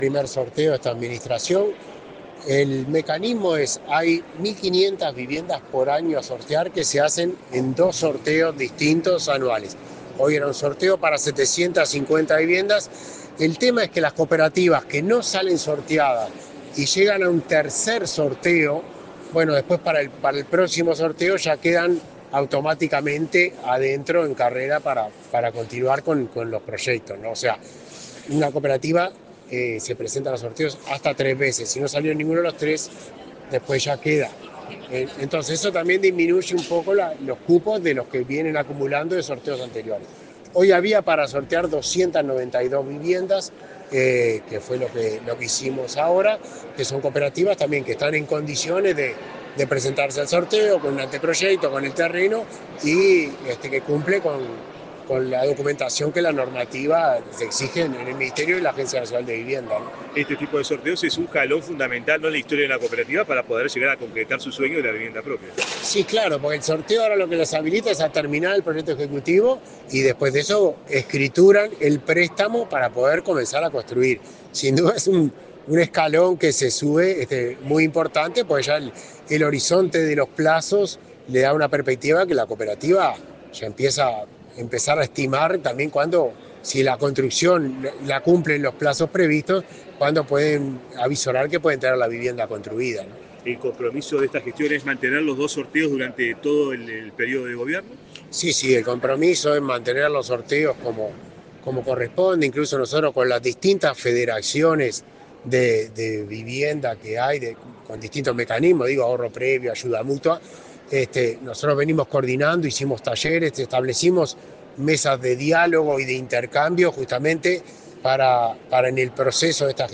Declaraciones del director nacional de Vivienda, Milton Machado
En ocasión del primer sorteo de cooperativas de vivienda de 2025, el director nacional de Vivienda, Milton Machado, dialogó con la prensa.